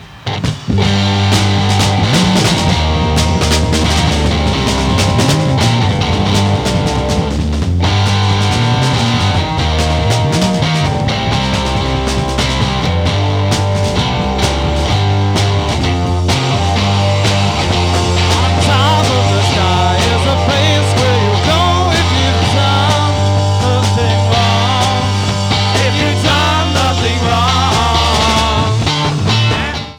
2021 Remix (Stereo)
2021 Remix (HD Sample)